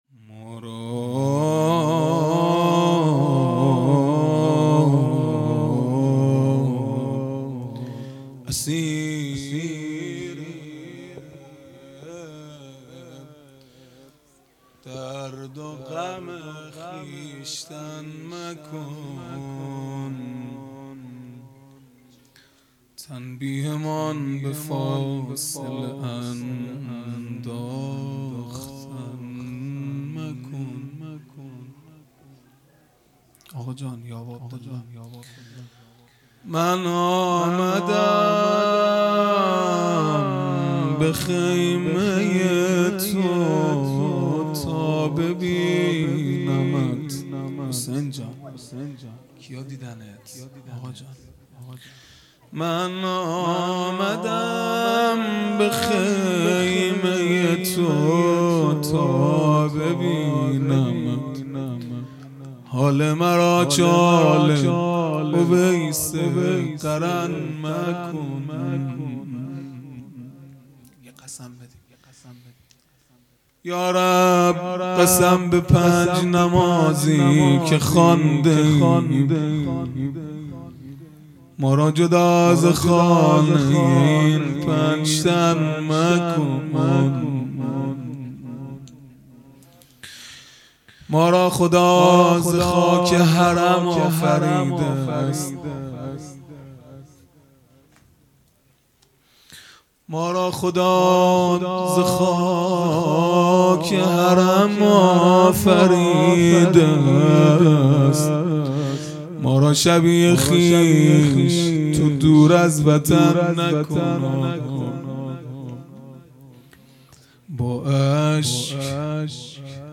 0 0 مناجات پایانی
فاطمیه 1443 | شب دوم